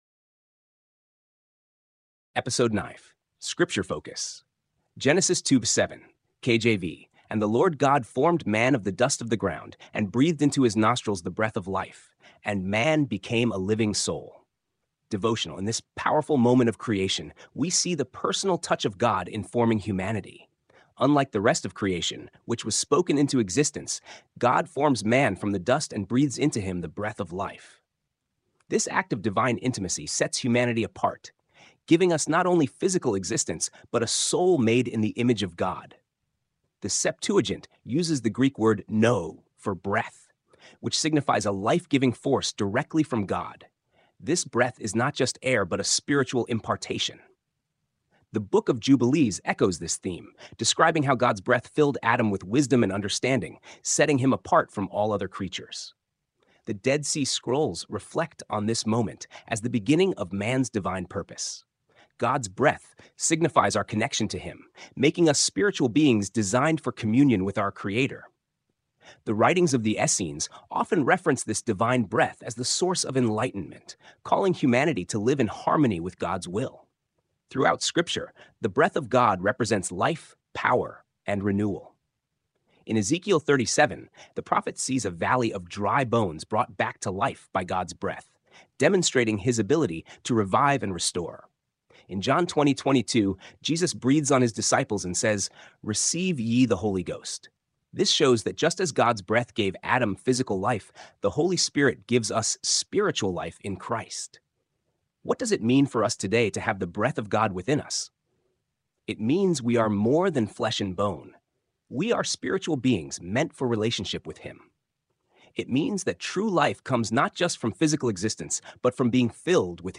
Weekly Devotional of KRRB Revelation Radio